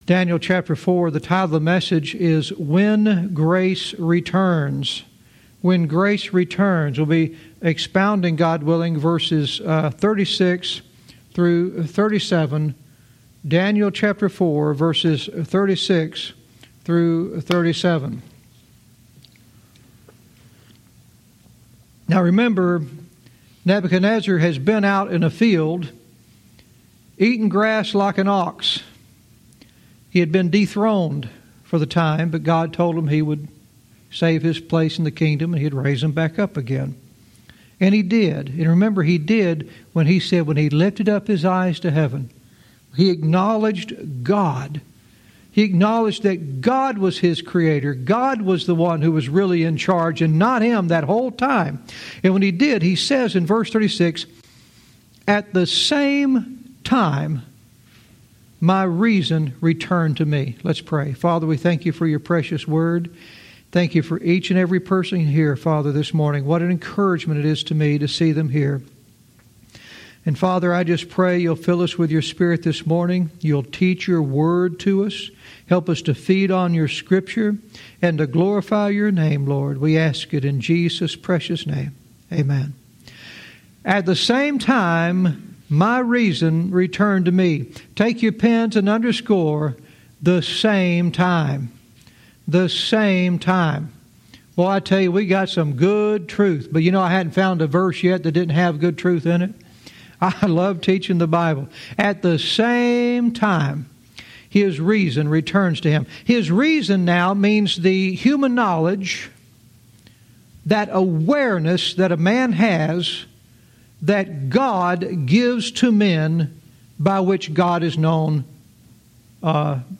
Verse by verse teaching - Daniel 4:36-37 "When Grace Returns"